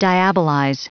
Prononciation du mot diabolize en anglais (fichier audio)
Prononciation du mot : diabolize